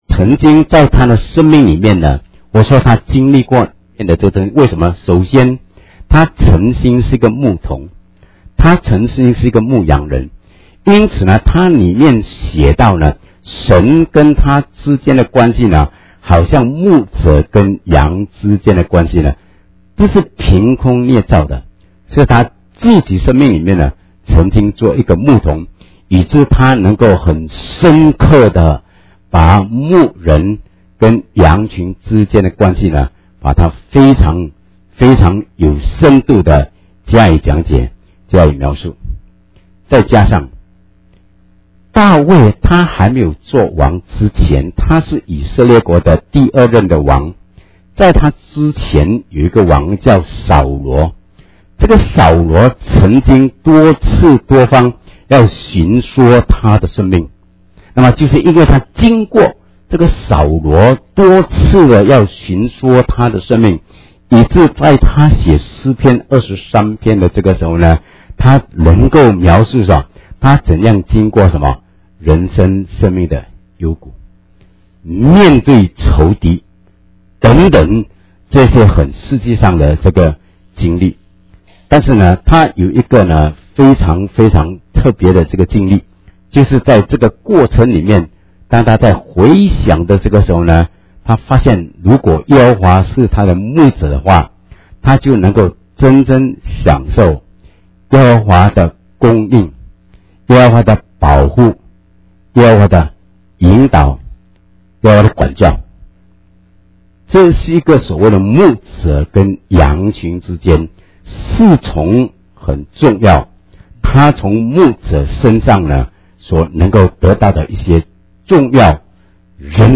Sermon Podcasts Downloads | Greater Kansas City Chinese Christian Church (GKCCCC)